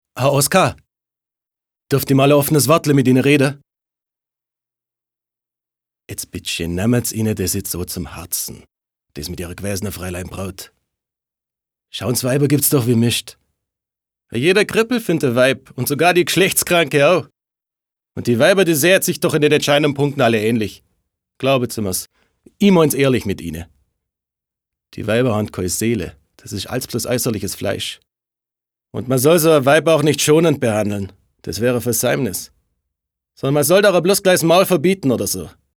deutscher Sprecher und Schauspieler.
Sprechprobe: Industrie (Muttersprache):